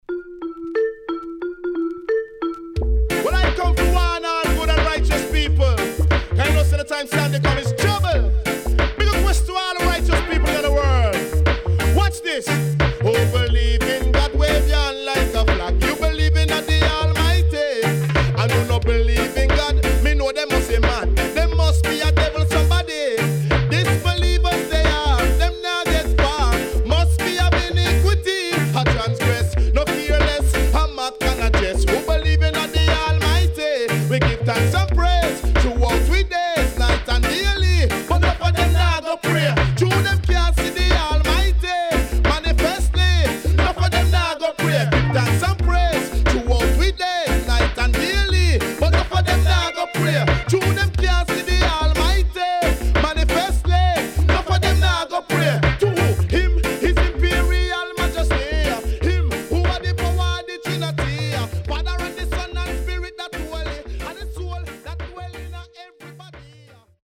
HOME > Back Order [DANCEHALL DISCO45]
Cover